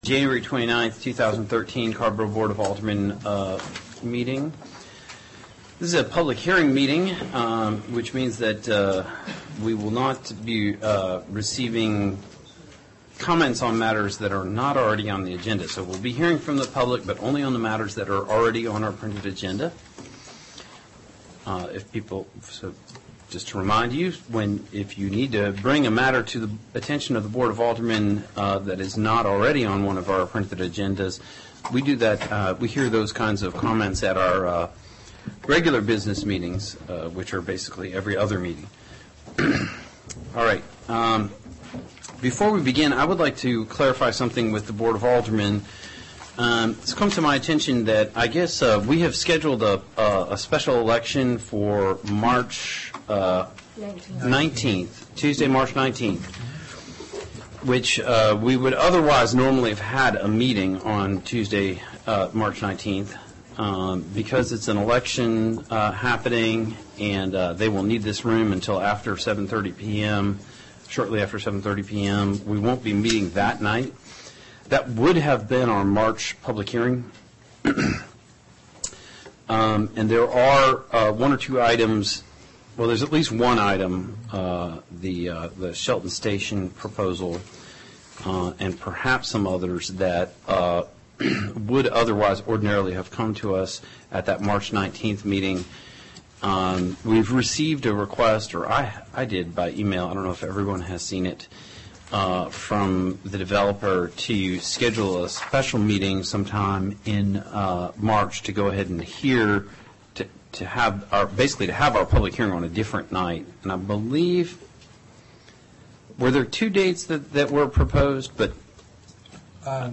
Watch and Hear Board Meeting (2hrs 39min; in Windows Media Video™ format) Watch and Hear Board Meeting (2hrs 39min; in RealPlayer™ format) Download and Listen to Board Meeting (37.4 MB; in MP3 format)
AGENDA CARRBORO BOARD OF ALDERMEN PUBLIC HEARING* Tuesday, January 29, 2013 7:30 P.M., TOWN HALL BOARD ROOM